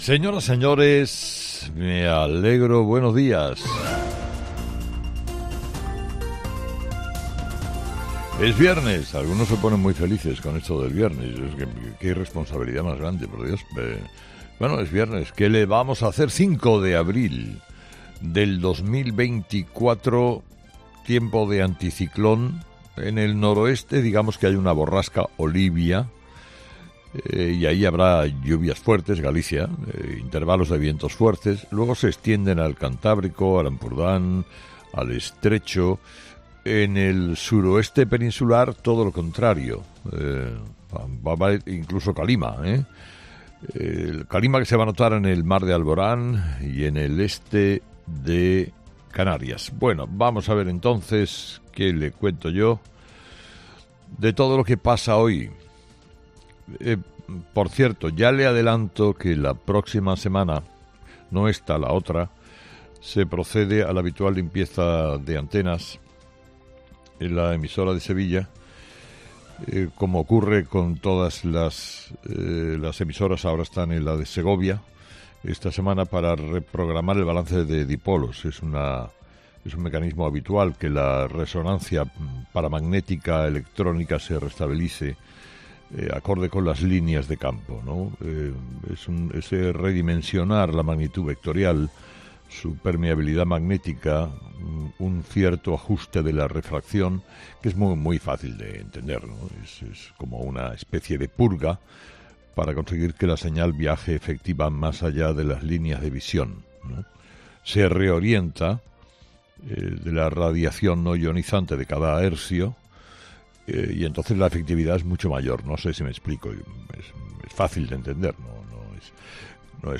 Escucha el análisis de Carlos Herrera a las 06:00 en Herrera en COPE del viernes 5 de abril
Carlos Herrera, director y presentador de 'Herrera en COPE', comienza el programa de este jueves analizando las principales claves de la jornada que pasan, entre otras cosas, por Pedro Sánchez y su vídeo desde el Valle de Cuelgamuros.